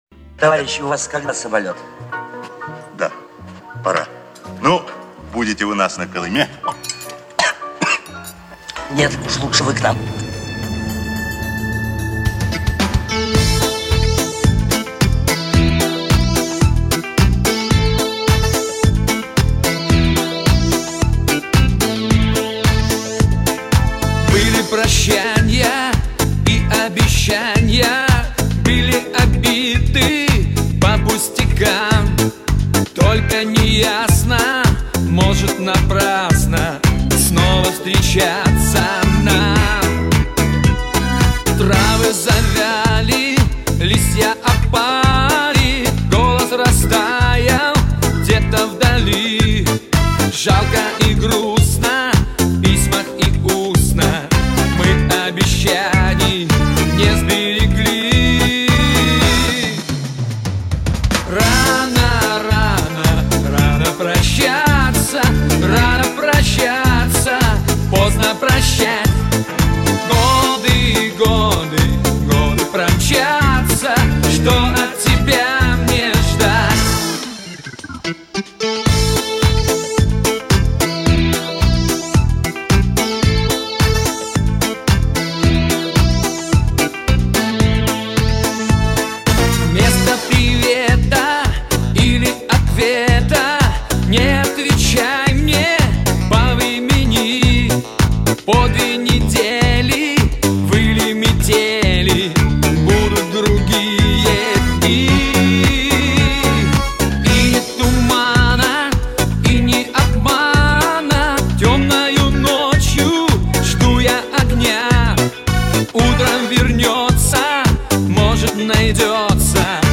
СТЕРЕО НЕ ОЧЕНЬ КОРРЕКТНОЕ- И РЕЗАТЬ НАЧАЛО????